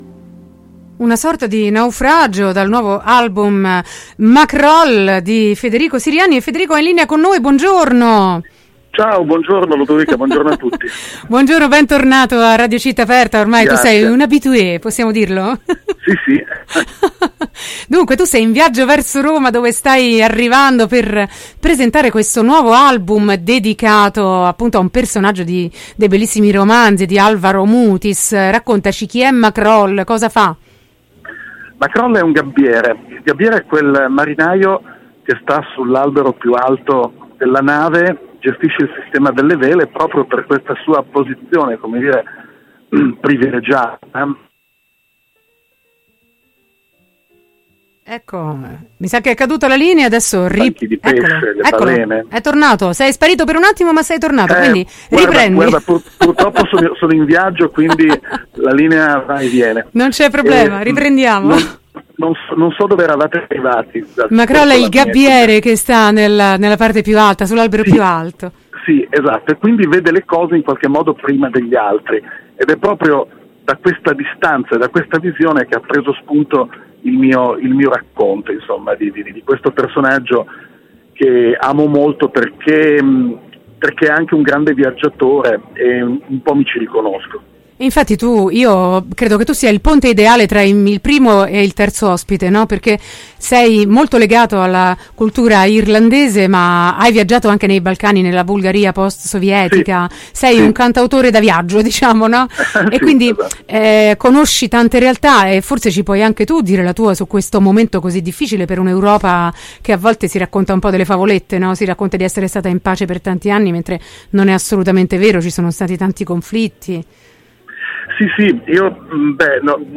In viaggio con il gabbiere Maqroll: intervista